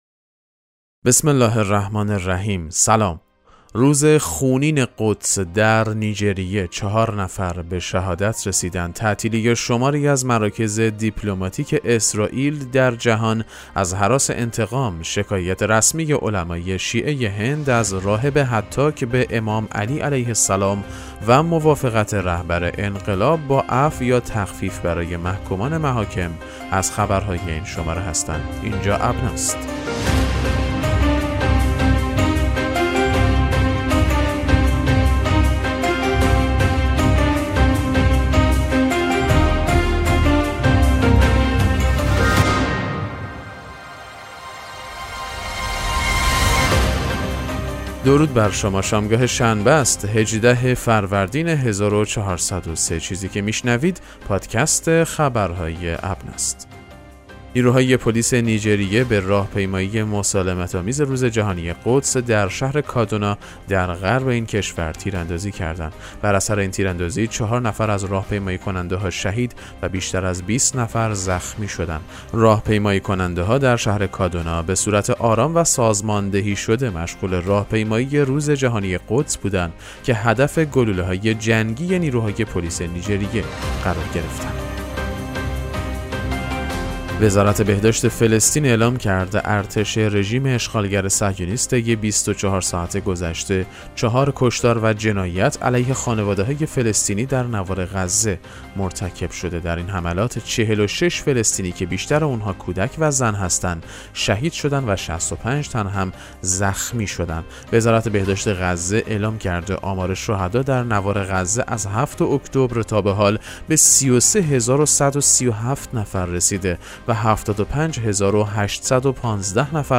پادکست مهم‌ترین اخبار ابنا فارسی ــ 18 فروردین 1403